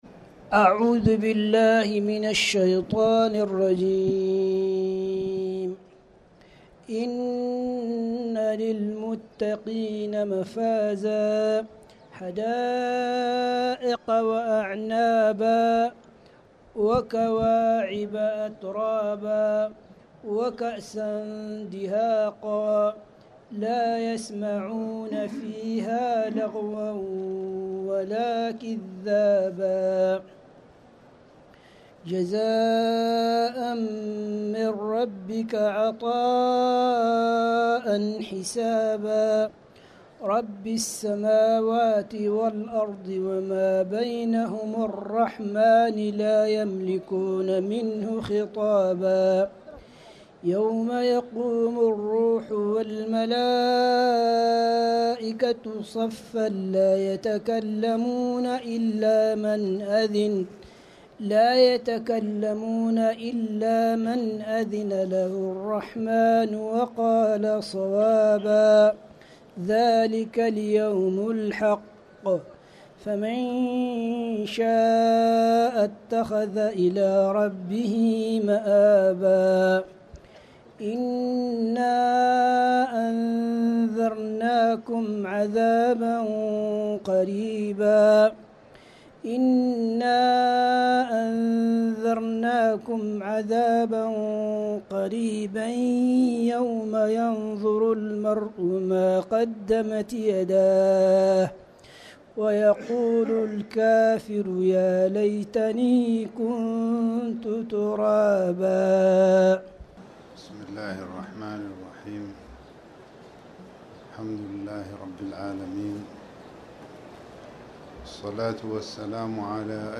تاريخ النشر ١٧ ربيع الثاني ١٤٤٠ هـ المكان: المسجد الحرام الشيخ